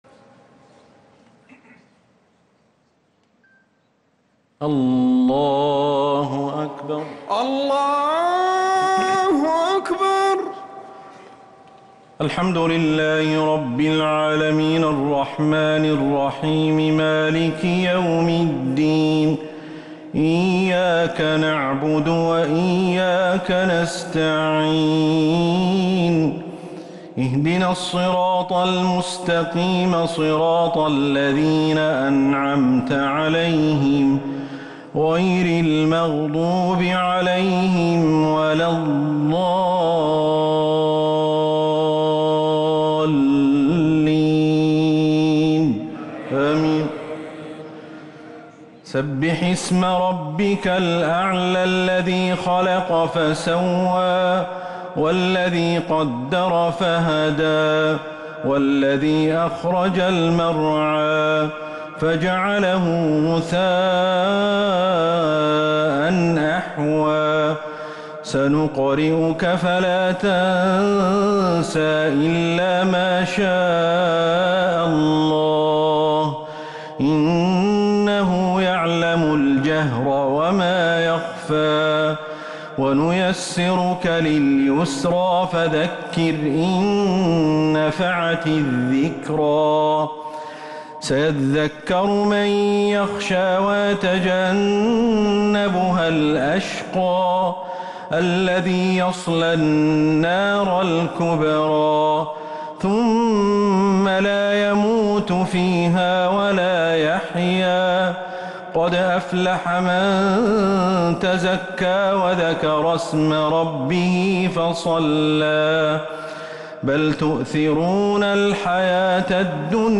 صلاة التراويح ليلة 4 رمضان 1444 للقارئ أحمد الحذيفي - الشفع والوتر - صلاة التراويح